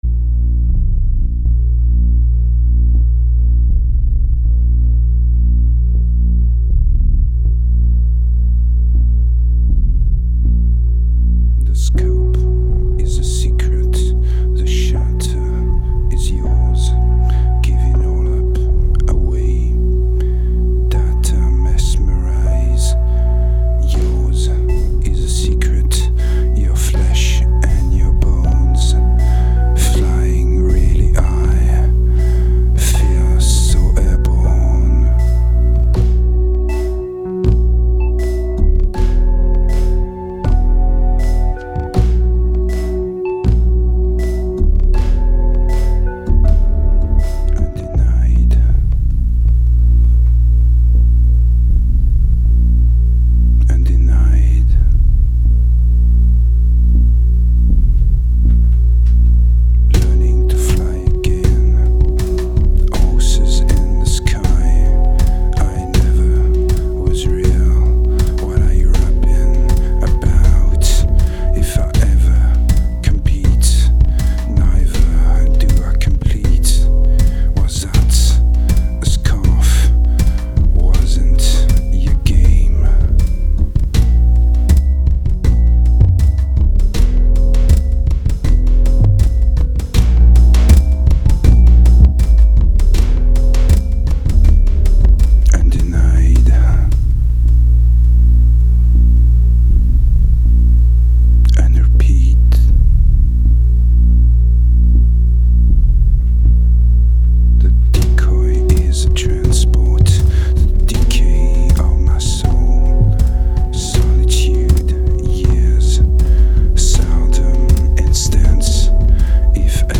2109📈 - -76%🤔 - 80BPM🔊 - 2008-10-18📅 - -397🌟